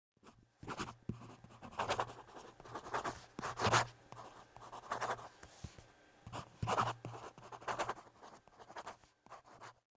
Mein Geräusch ist ein sehr leiser, zarter Klang der im Normalfall dem Untergang geweiht ist, und dem niemand Beachtung schenkt.
Wir sprechen vom Klang einer Unterschrift.